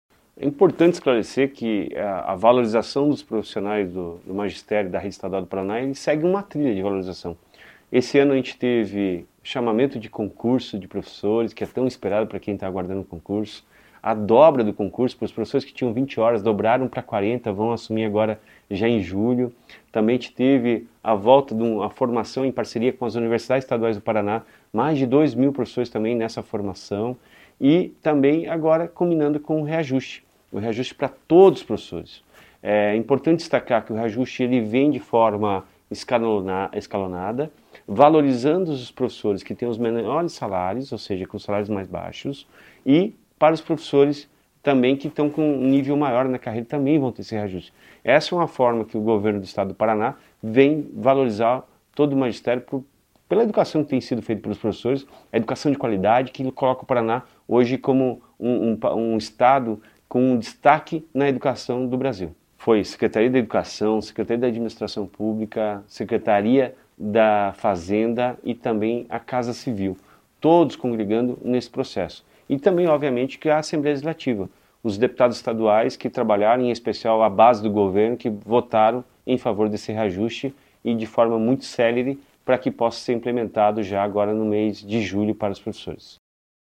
Sonora do secretário da Educação, Roni Miranda, sobre o reajuste salarial dos professores da rede estadual do Paraná